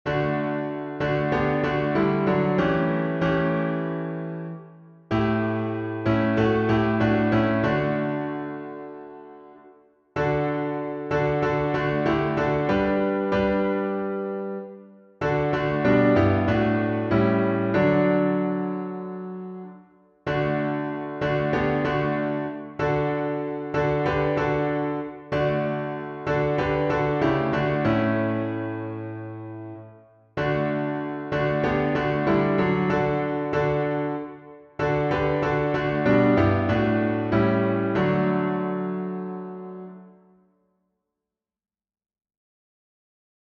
Key signature: D major (2 sharps) Time signature: 4/4